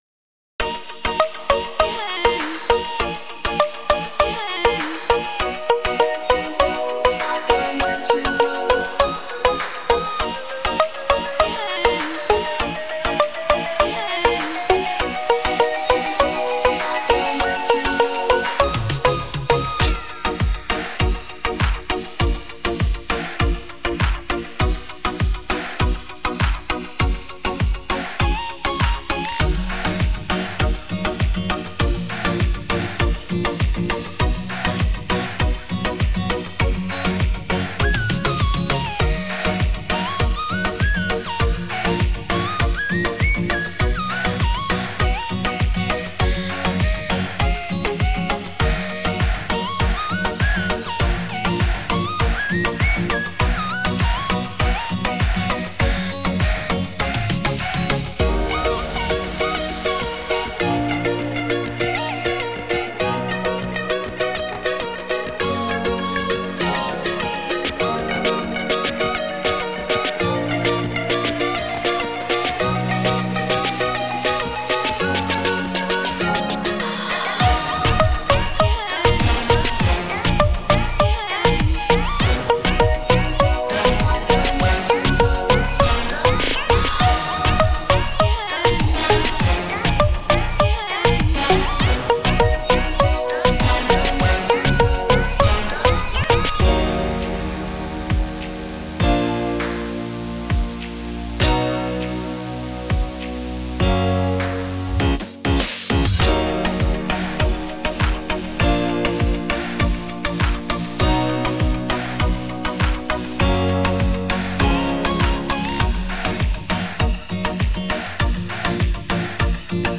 おしゃれ 異国風 FREE BGM